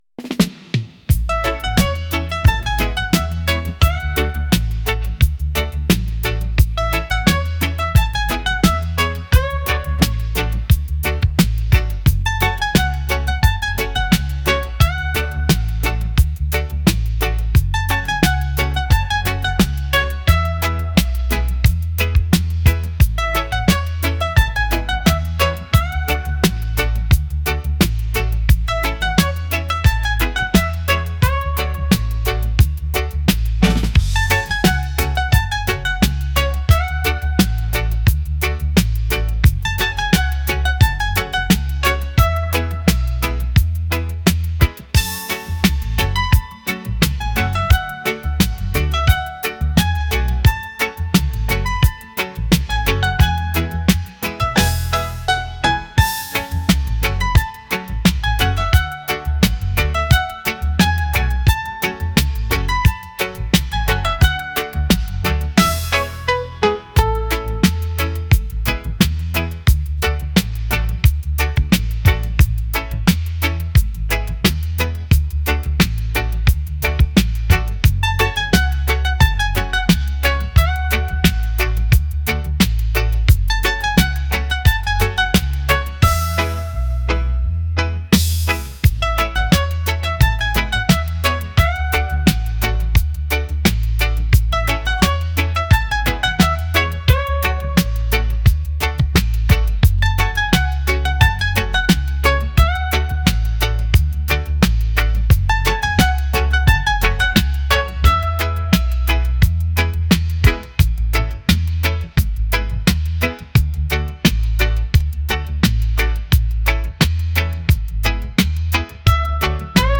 reggae | smooth | romantic